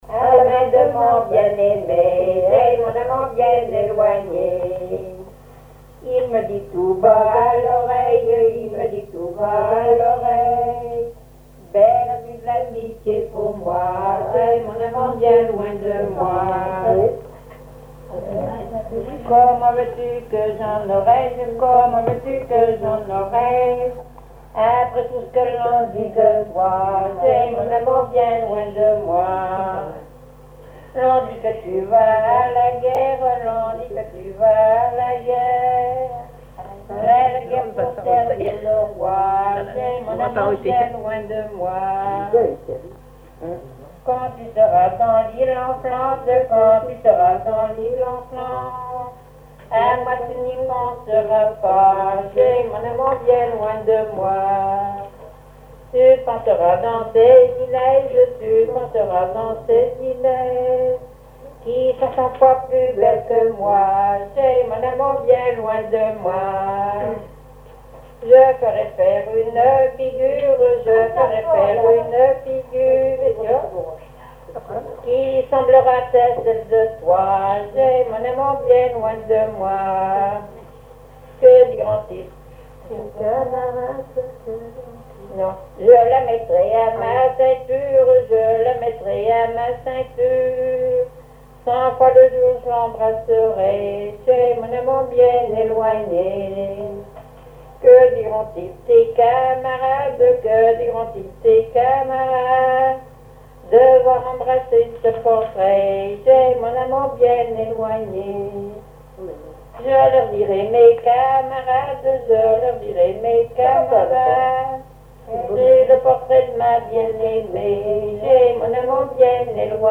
danse : ronde à la mode de l'Epine
Veillée de chansons
Pièce musicale inédite